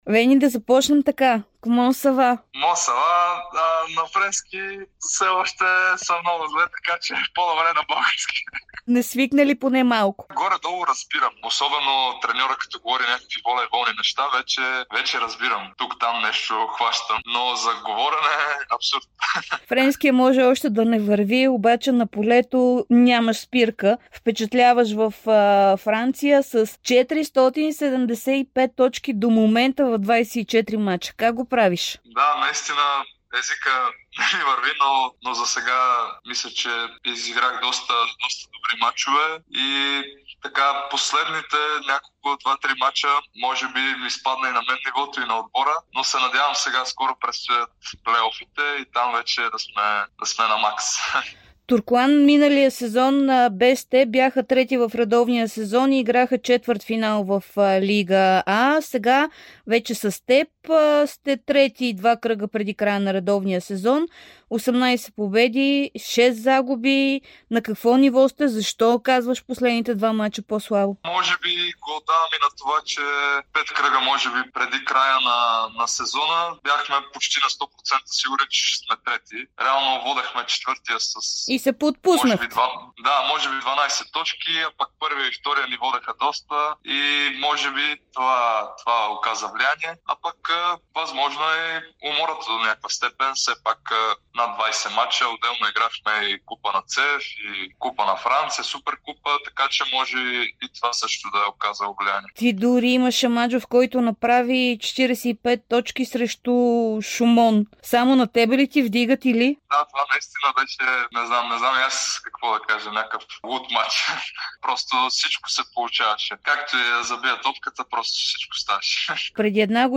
Венислав Антов, родният волейболен национал и актуален състезател на френския Туркоан, даде интервю за Дарик и dsport.